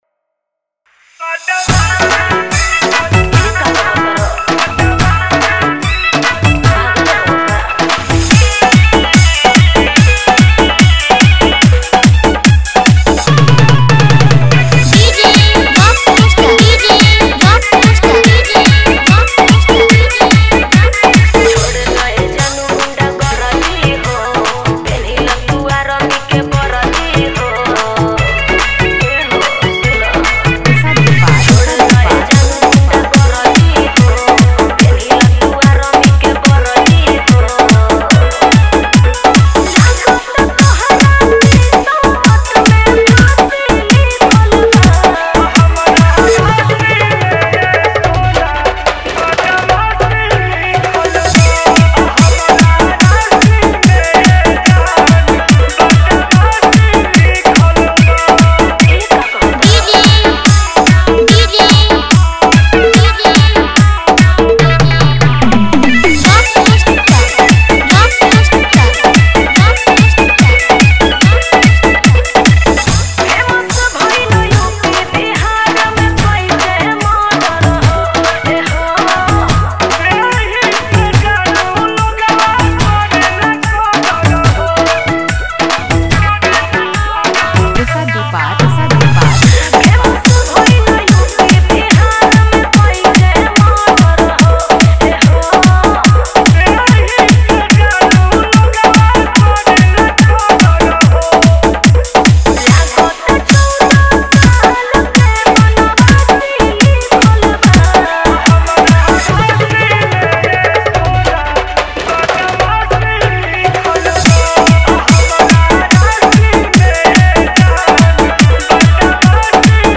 Category:  Bhojpuri Dj Remix